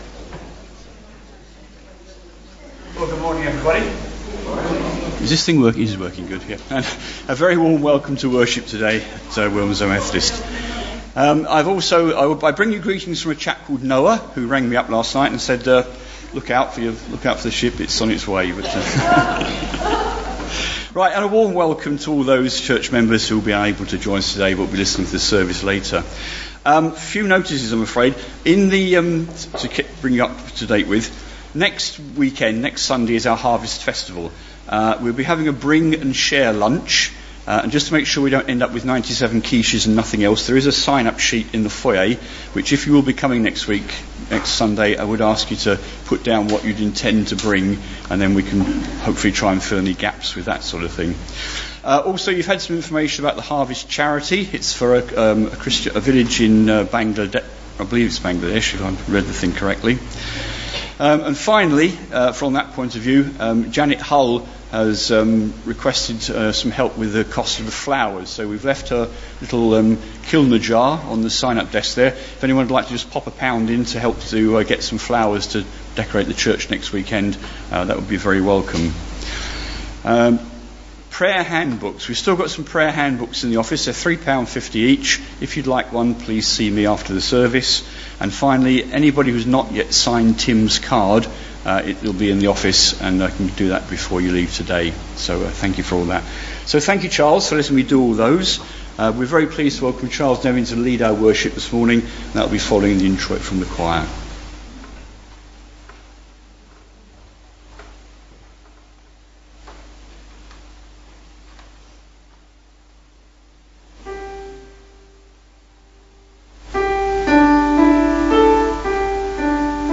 Morning worship
Genre: Speech.